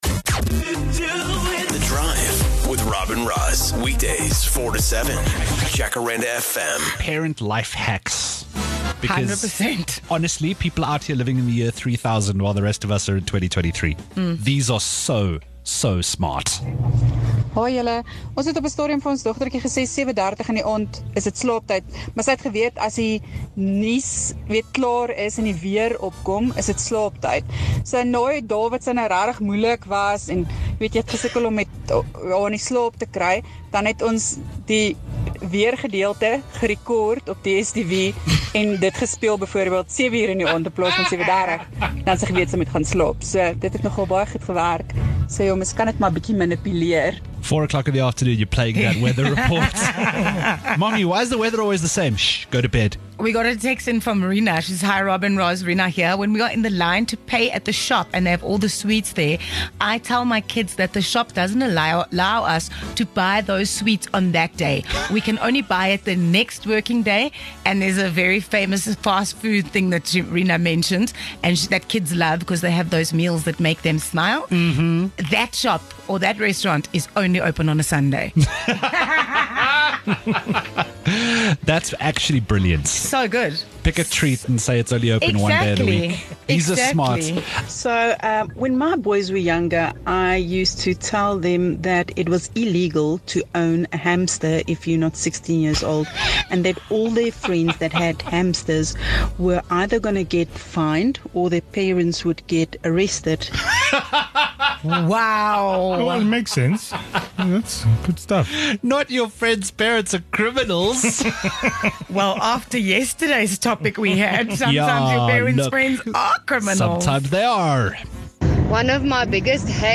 18 Apr Jacaranda FM parents share the lies they tell their kids